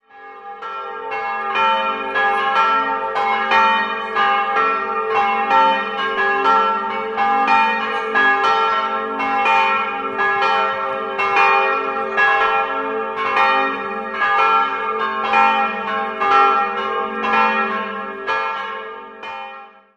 3-stimmiges Gloria-Geläute: as'-b'-des''